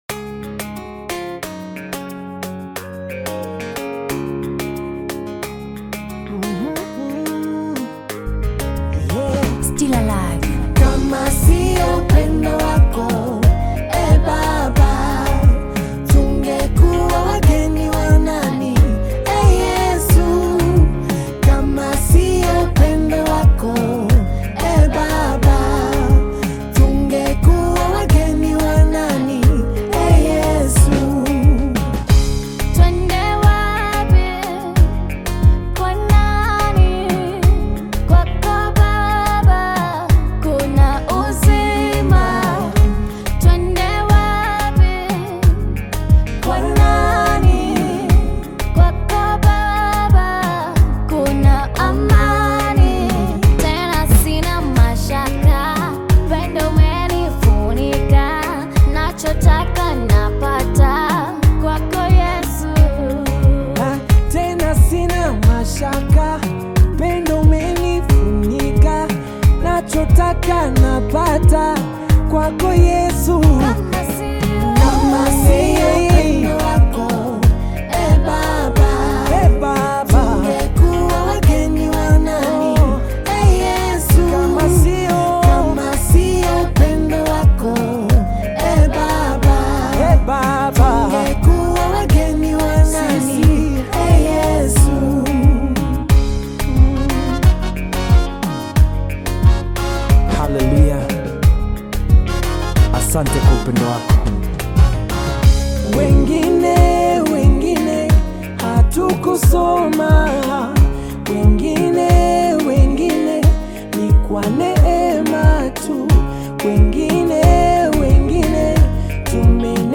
Tanzanian gospel singer
gospel song
African Music